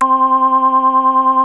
JNO 4 C5.wav